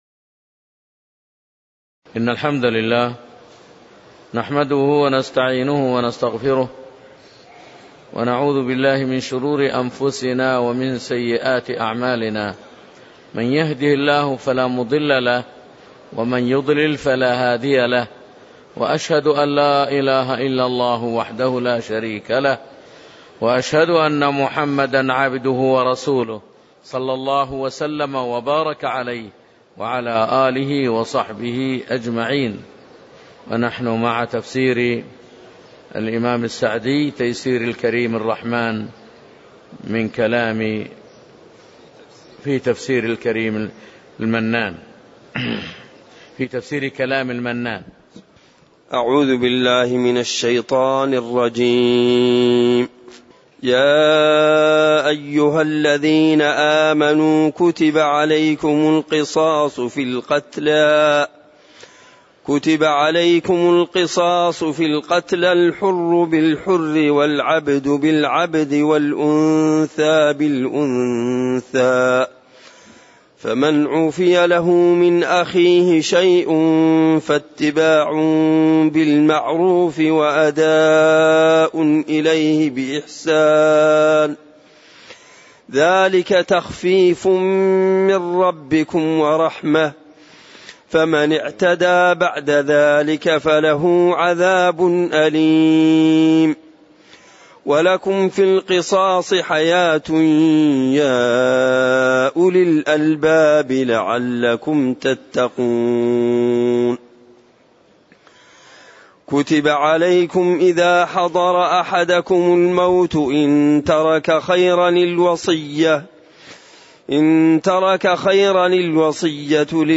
تاريخ النشر ٢٣ شعبان ١٤٣٨ هـ المكان: المسجد النبوي الشيخ